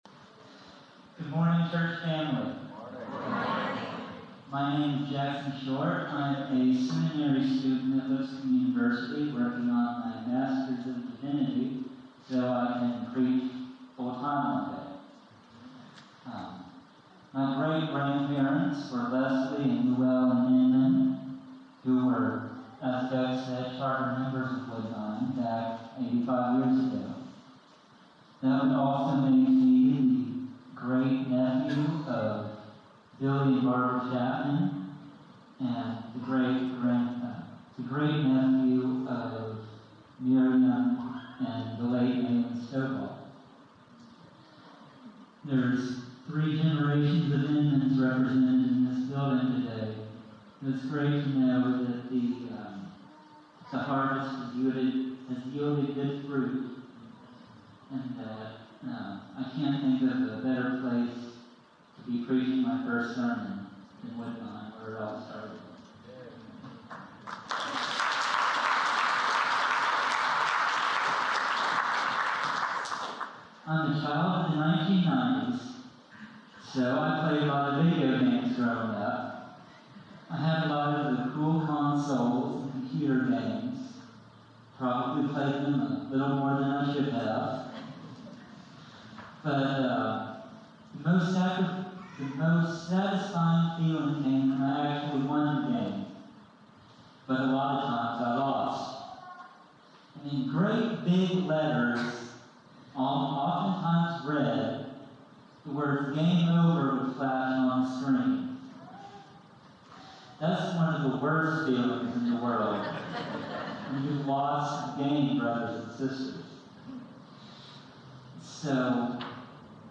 The Great Commandment - Sermon - Woodbine